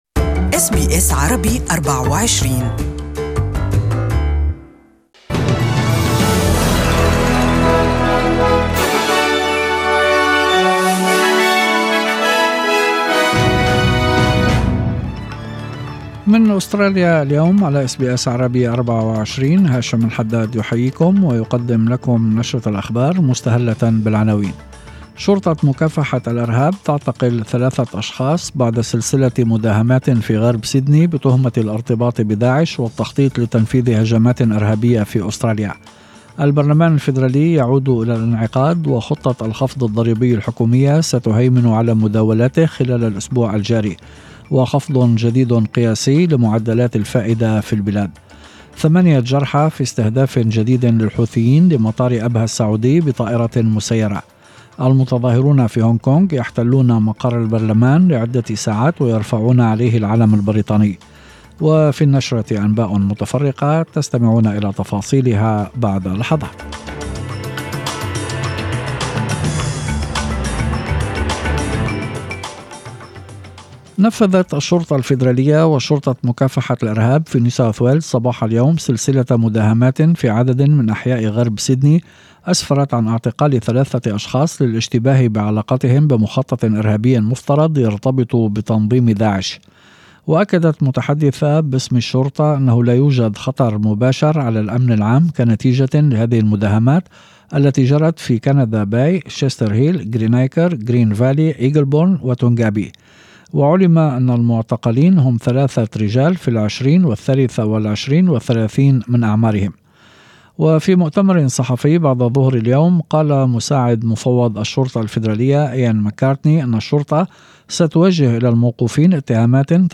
أخبار المساء: حقوق الأبوريجينيين والضرائب في أول يوم للبرلمان
يمكن الاستماع لنشرة الأخبار المفصلة باللغة العربية في التقرير الصوتي أعلاه .